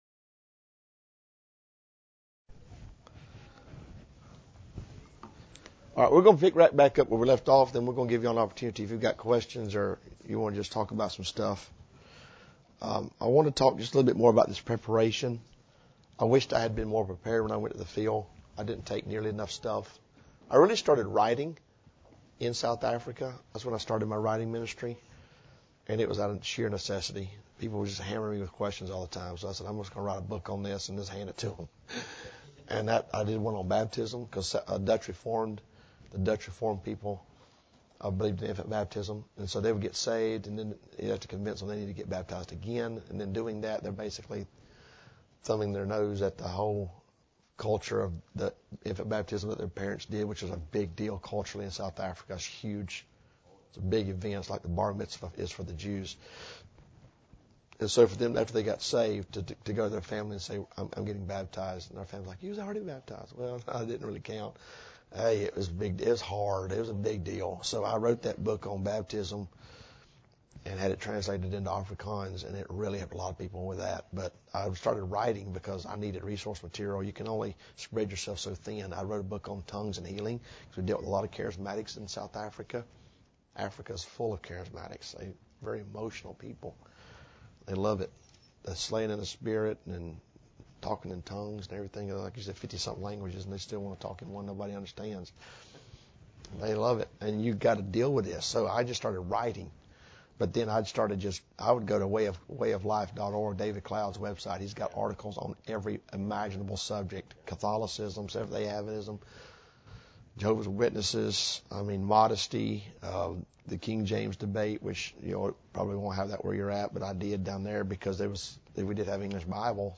Missions Conference